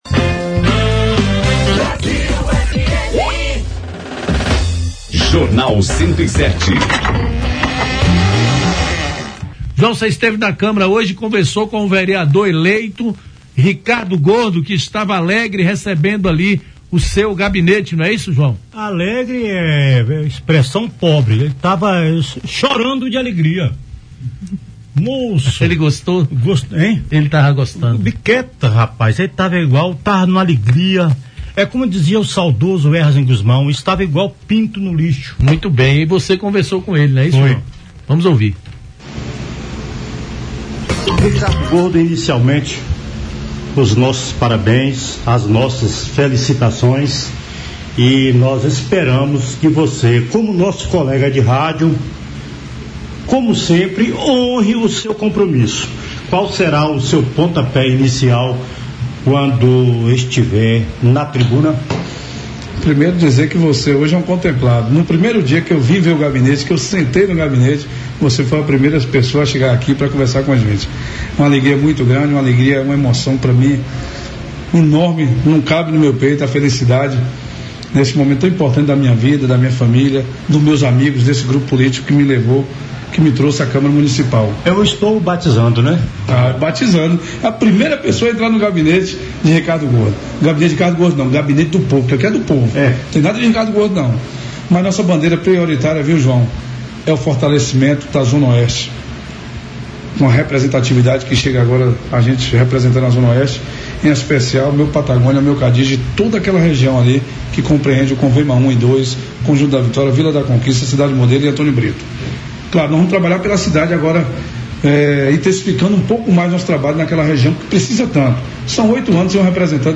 Em tom leve e descontraído, o vereador falou sobre a importância de sua trajetória e o apoio recebido do grupo político que o elegeu. Ele fez questão de destacar que o “Gabinete do Povo” não tem nada a ver com seu nome, mas com a missão de representar a comunidade de forma dedicada e comprometida, com foco na Zona Oeste.